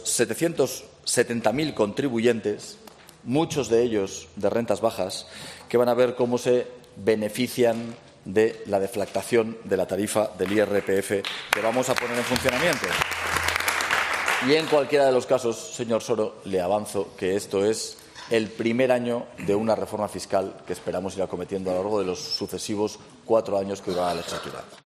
El presidente Azcón anuncia una de las medidas fiscales que aplicarán: la deflactación del IRPF.